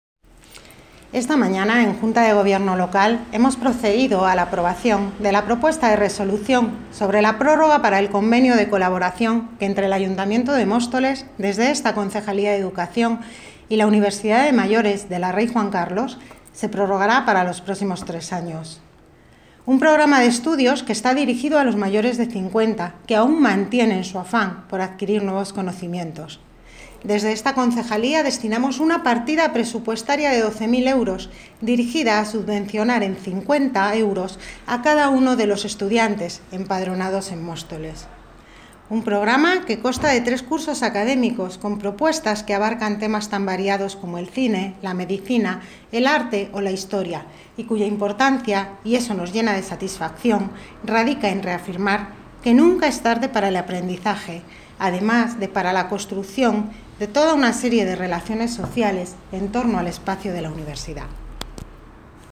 Audio - Isabel Cruceta (Concejala de Educación ) Sobre Universidad de Mayores